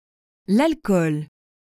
Pronunciation
ahl-kohl or alkɔl.